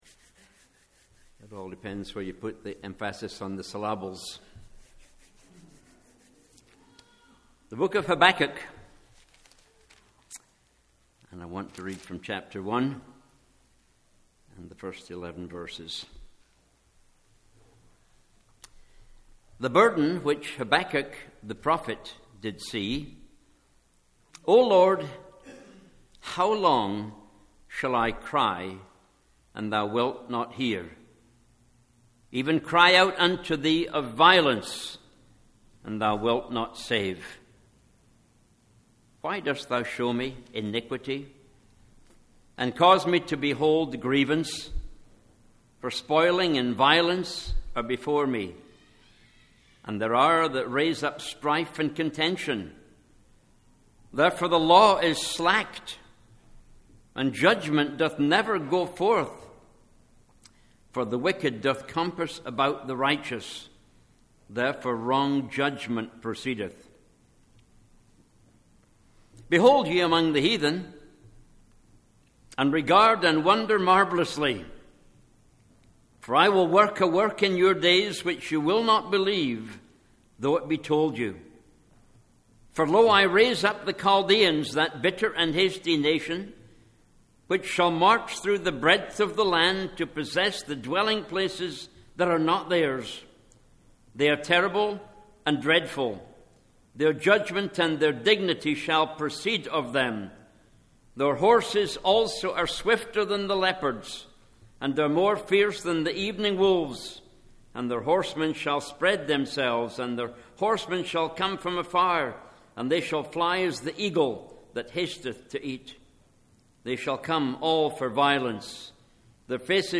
Sermons - Martinez Bible Chapel - Page 8